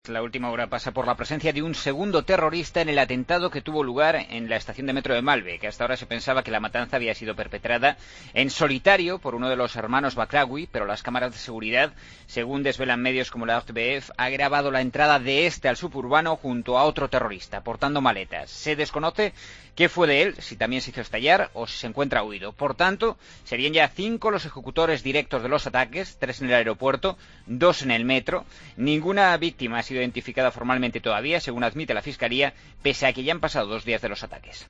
AUDIO: Los autores de las masacre de Bruselas son cinco. Crónica